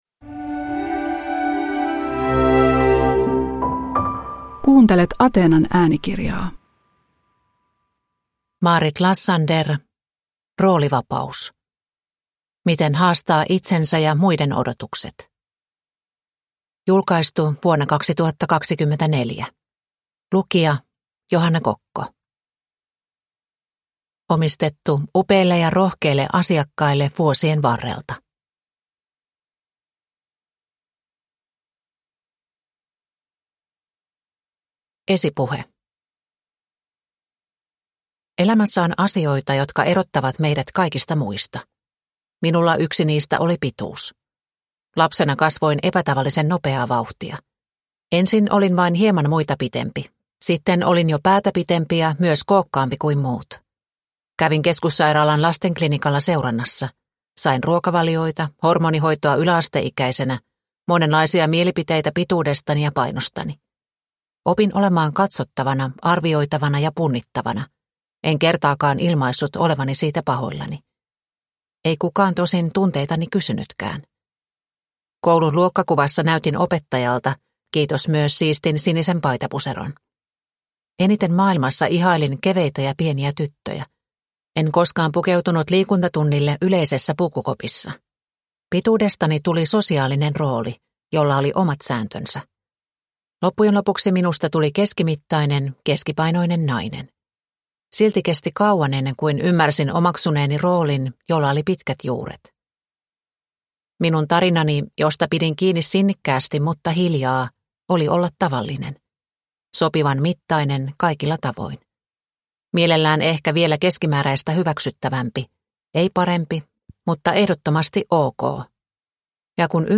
Roolivapaus – Ljudbok